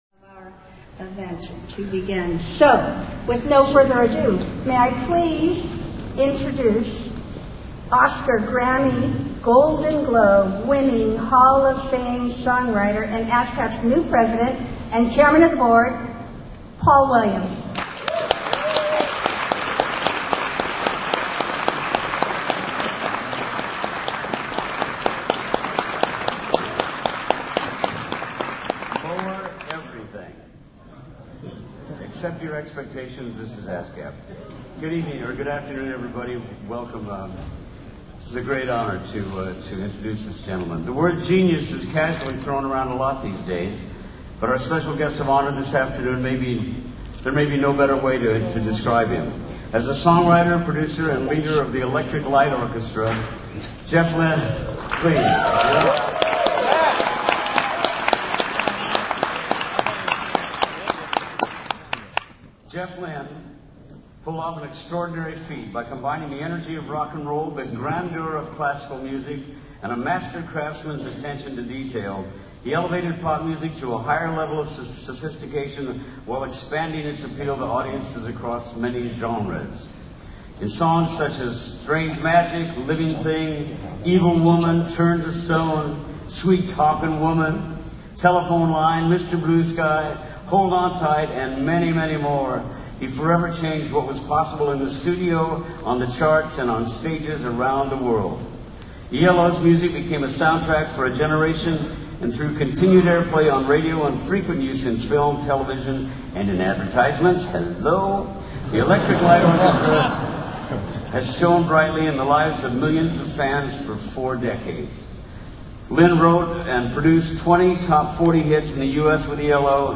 Special presentation of ASCAP's GOLDEN NOTE AWARD and "I Create Music" interview with JEFF LYNNE April 24
Los Angeles, CA